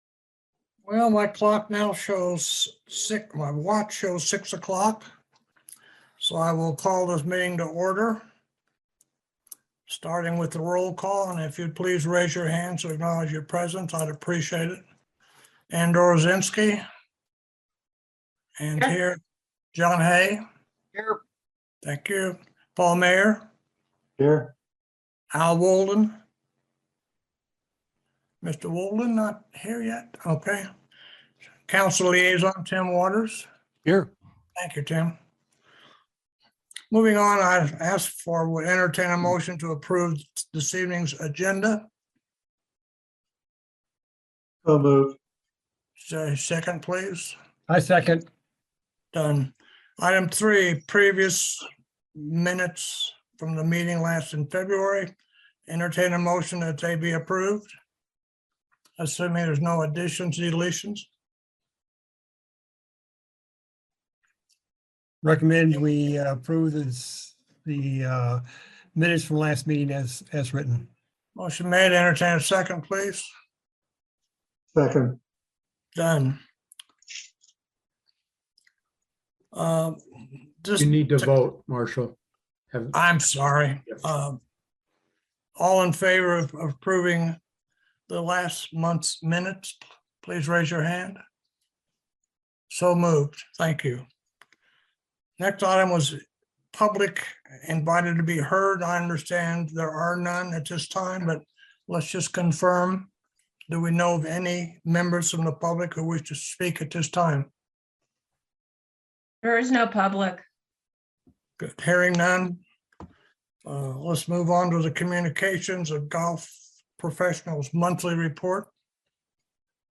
The Longmont Golf Course Advisory Meeting recorded on Mrach 28, 2022